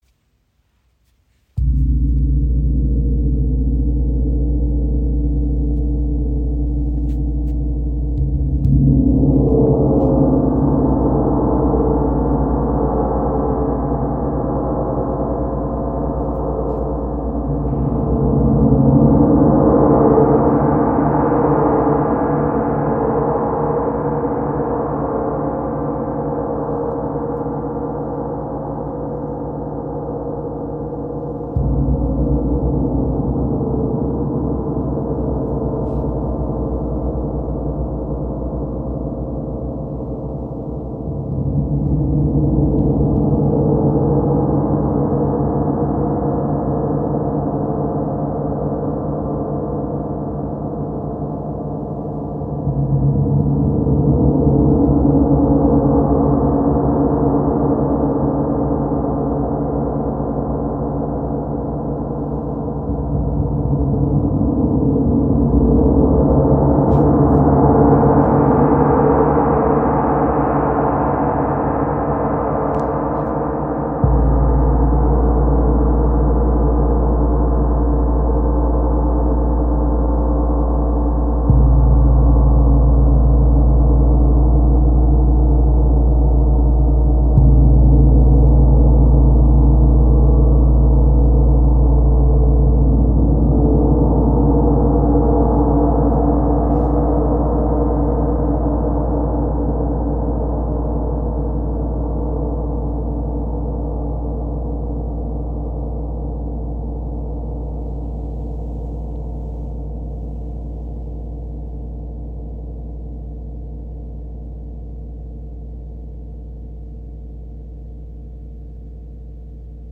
Diese zentrale Bearbeitung verstärkt die Resonanz, lässt Obertöne klarer aufschwingen und verleiht dem Gong eine besondere Tiefe und Lebendigkeit.
Durch die sorgfältige Handarbeit entsteht ein tragender Grundton, der von einer vielschichtigen Obertonstruktur begleitet wird. Die Töne schwingen lange nach, verbinden sich harmonisch und erzeugen eine weite, raumfüllende Klanglandschaft.
• Material: Neusilber (Kupfer, Nickel, Zink)
• Klangcharakter: Tragender Grundton mit vielschichtigen, harmonischen Obertönen